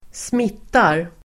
Uttal: [²sm'it:ar]